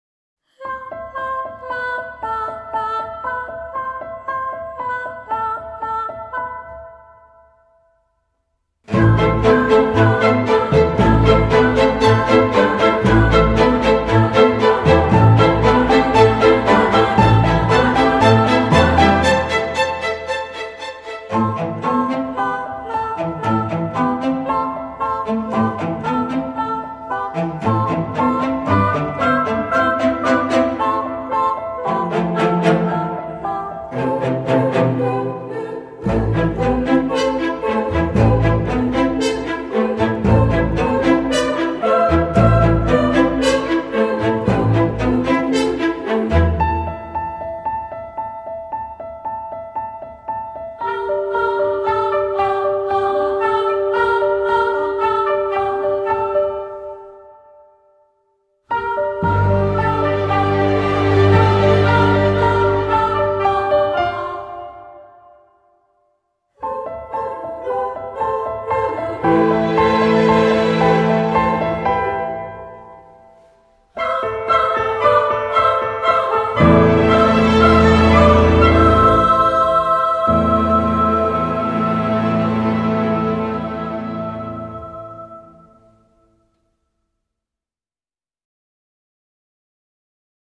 Poétique, délicate, épurée et légère
voix samplées